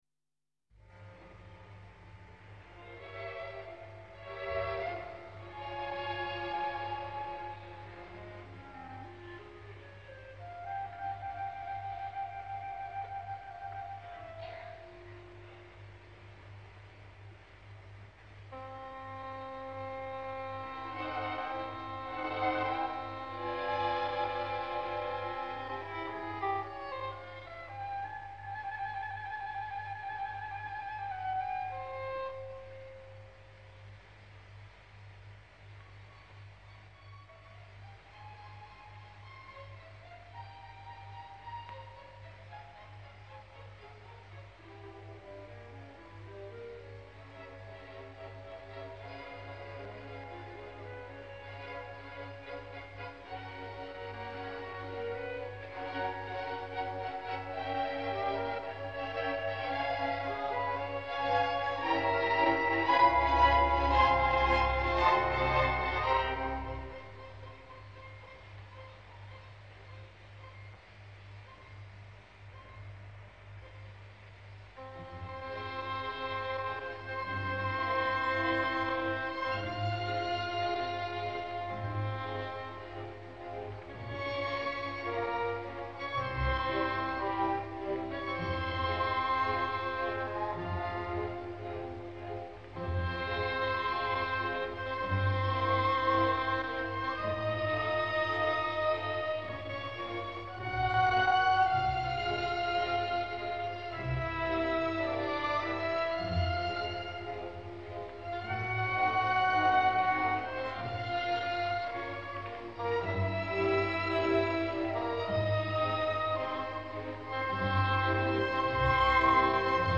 Ha mort la soprano que ho va cantar tot i bé.
En aquesta improvisada crònica a l’espera d’un post com cal, us deixo a la Gencer cantant la darrera escena de la Lady Macbeth a Palermo a l’any 1960.